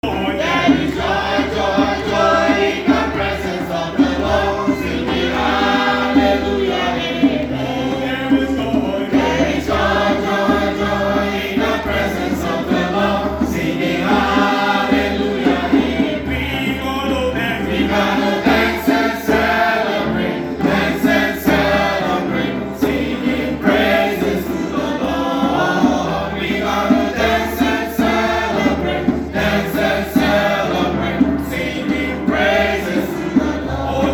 Men’s Choir – Holyrood Mennonite Church
Joy, Joy, Joy in the Presence of the Lord: Holyrood Church Men’s Choir
piano.